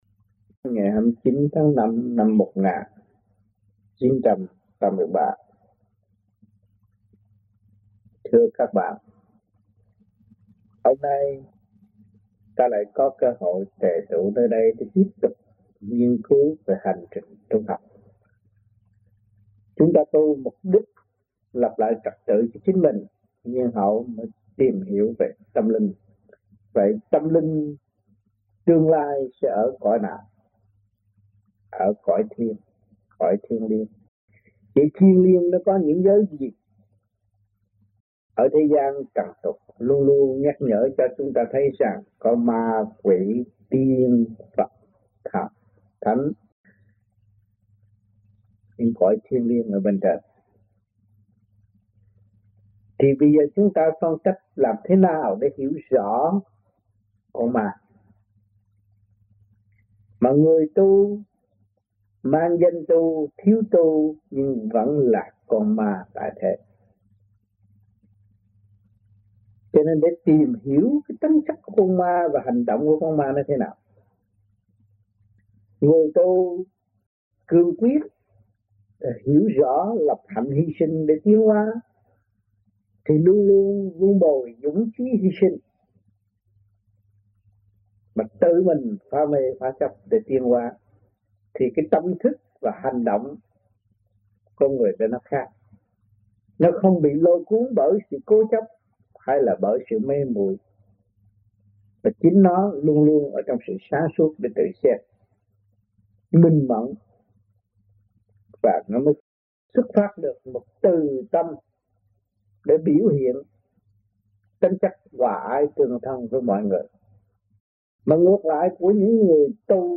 Băng Giảng